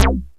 ACIDBAS1.wav